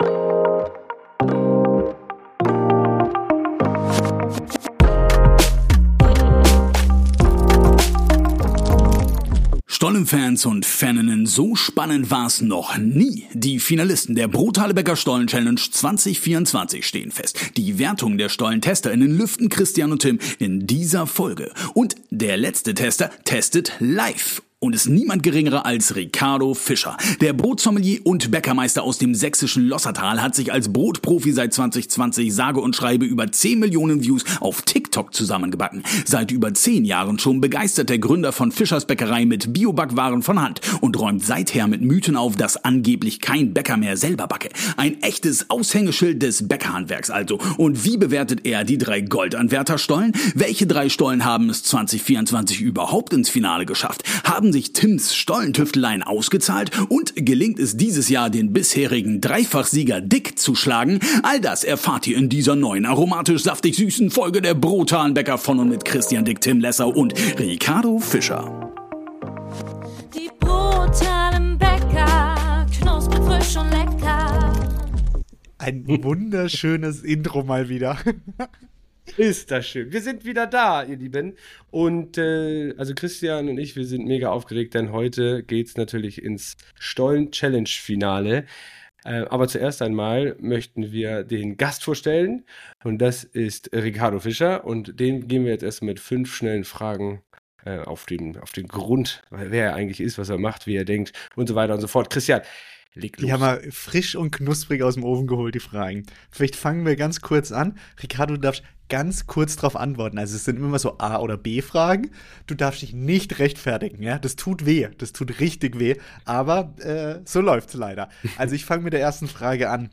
Doch damit nicht genug: Der letzte Tester testet: live am Podcast-Mikrofon.